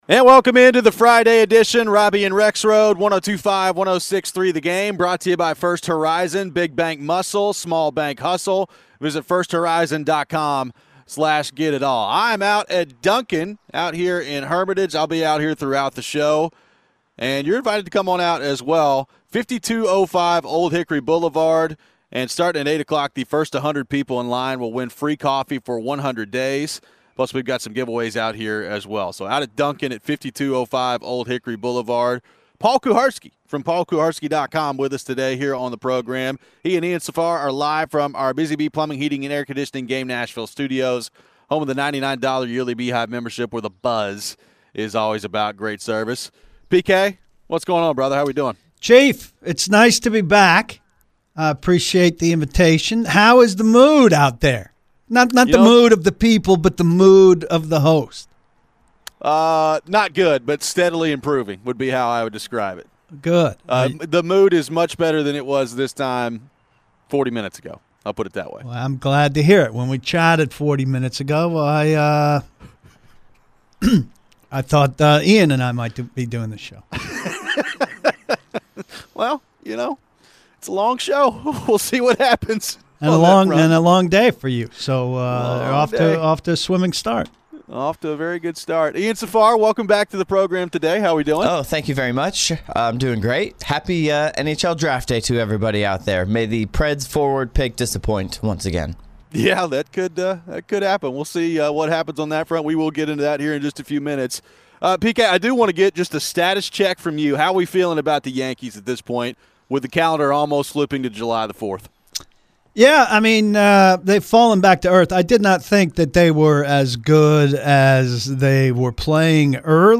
We get into some Titans talk and how different might the offense look in the 2nd year under Brian Callahan? We take your phones.